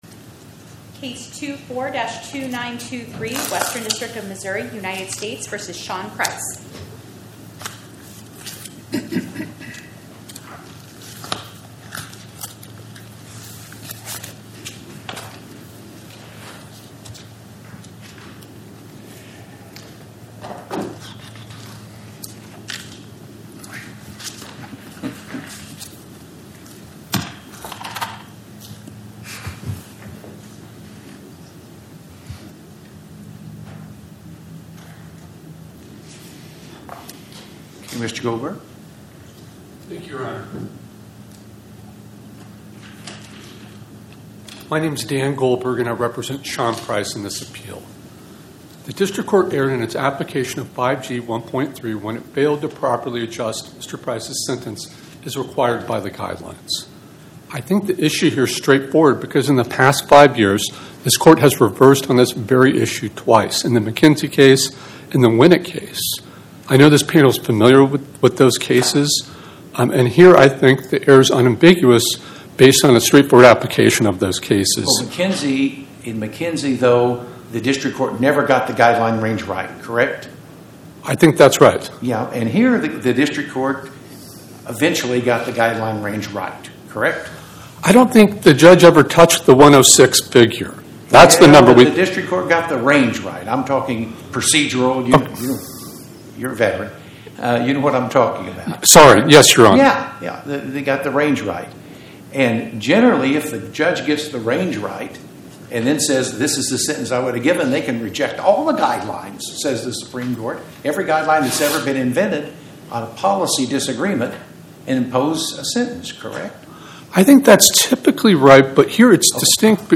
Oral argument argued before the Eighth Circuit U.S. Court of Appeals on or about 09/18/2025